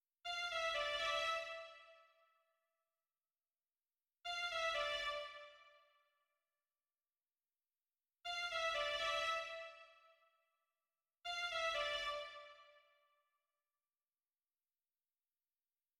电影主题 " 02259 悲伤的电影交响乐循环
描述：循环，可作为使用VST乐器创作的悲伤或戏剧性场景的背景音乐管弦乐电影类型循环。
Tag: 电影 管弦乐 反思 悲伤 交响乐 主题